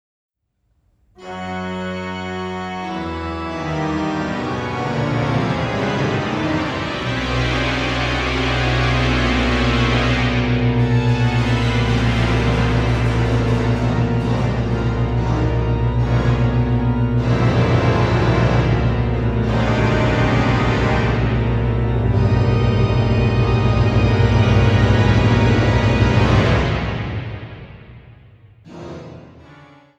Peter-Orgel der Kunst-Station Sankt Peter Köln